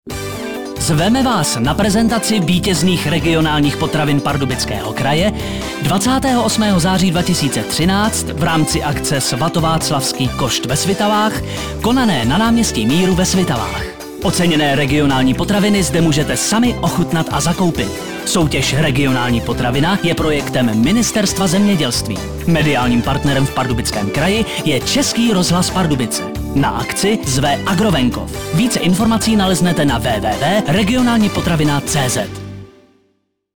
Upoutávky v Českém rozhlase Pardubice na ochutnávky  vítězných regionálních potravin v Pardubickém kraji: